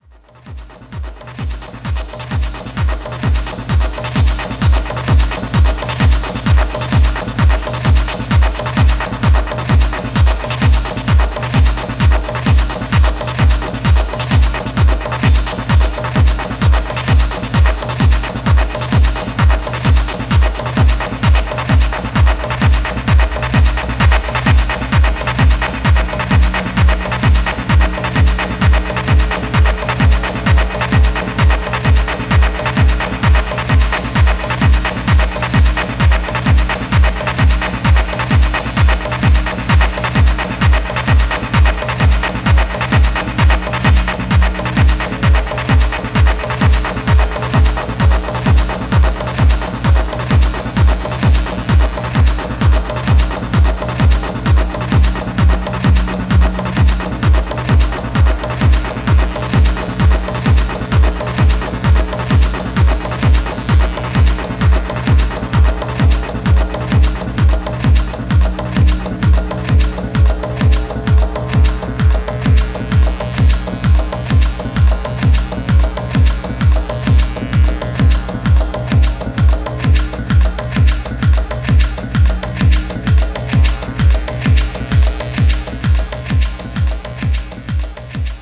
アフロ・トライバルな跳ね感がトランシーな
ポスト・パンキッシュ・レイヴ！